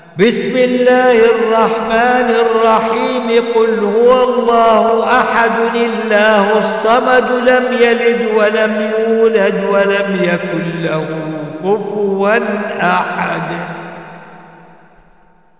دروس التجويد وتلاوات